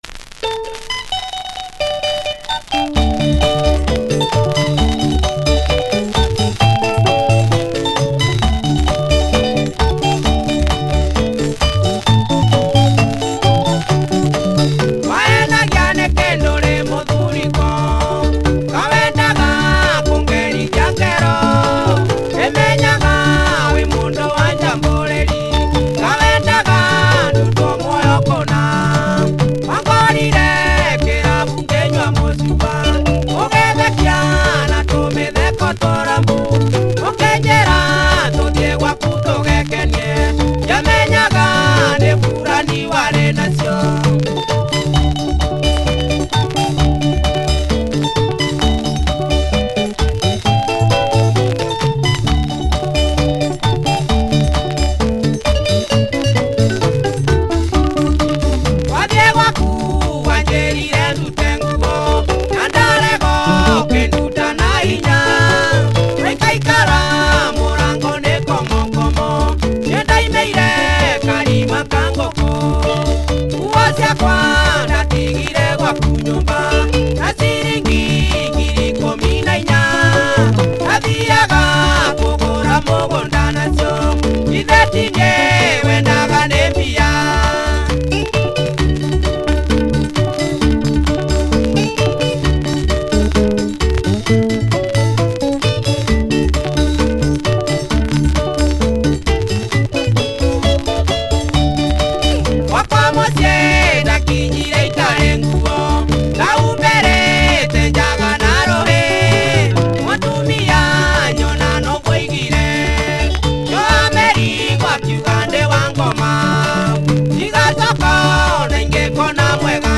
Quality Kikuyu Benga